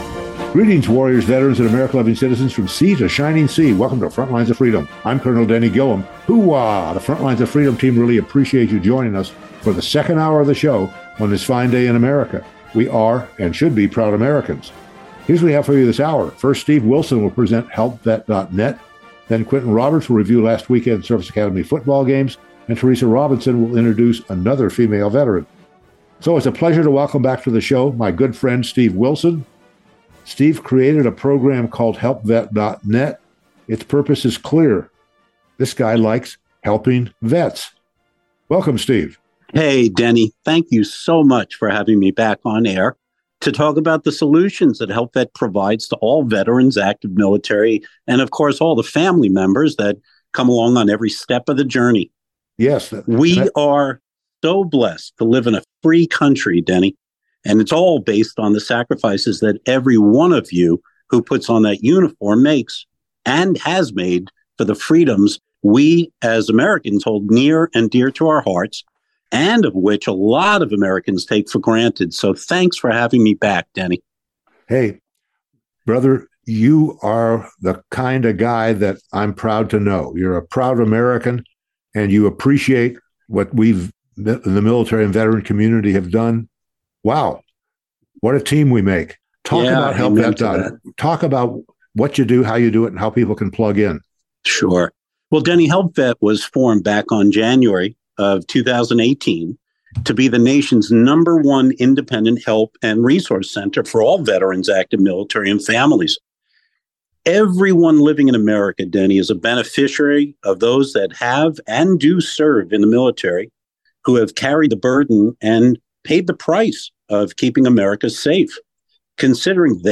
Frontlines_of_Freedom_interview-11-short.mp3